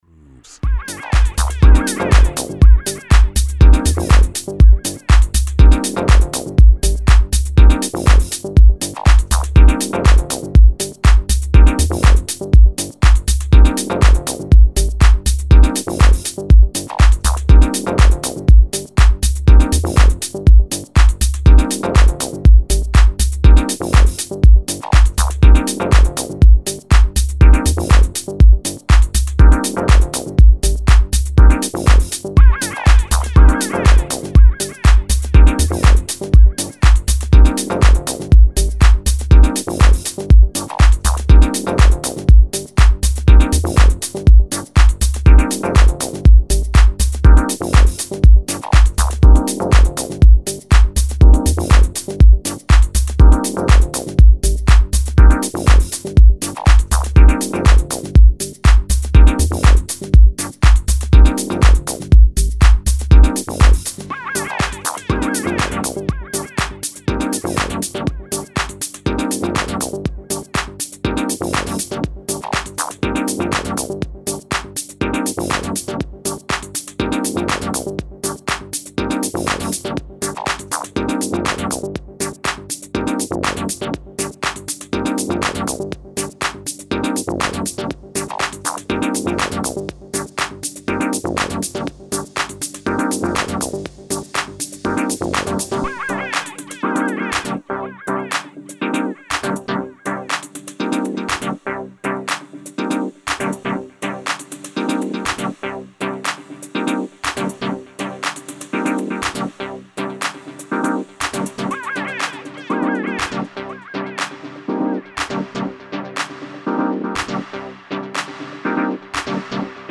in a deep and groovy way
Style: Deep House / Tech House